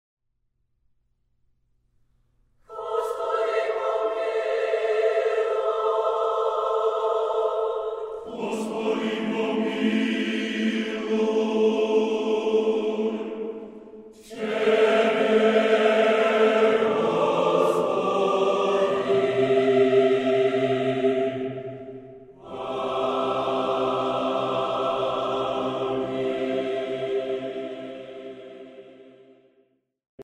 Super Audio CD
a gorgeous sacred choral suite.